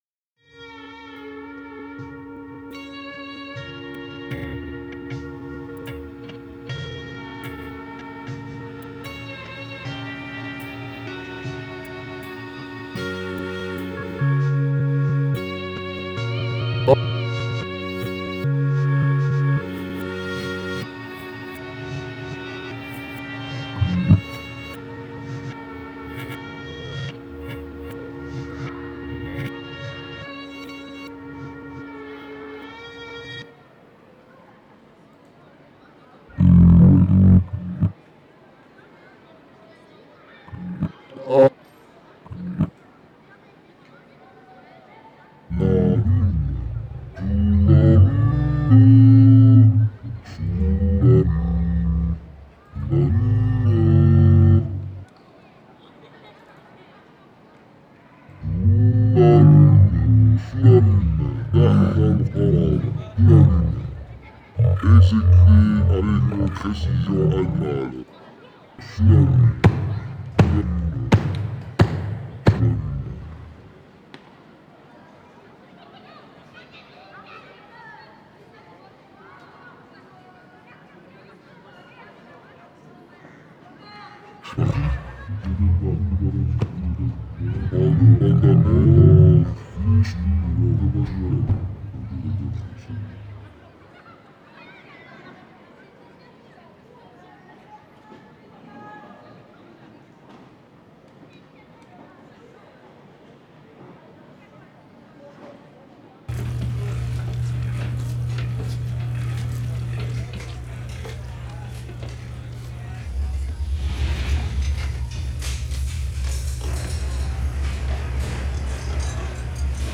Emission radio Création sonore | sur le 100.4 FM - Vous souhaitez partager une information ?
D’abord diffusée sur la radio dioise (Rdwa), l’émission Cosmorama est une parenthèse de poésie cosmique.